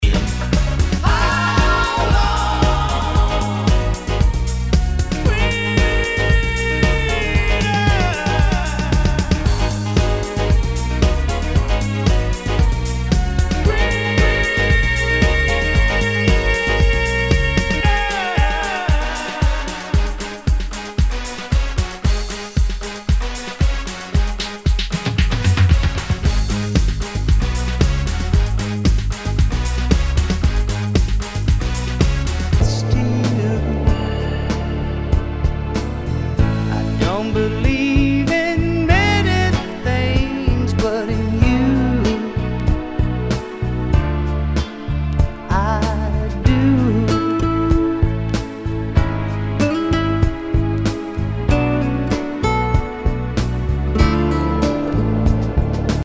HOUSE/TECHNO/ELECTRO
ナイス！ヴォーカル・ハウス / ダウンテンポ・クラシック！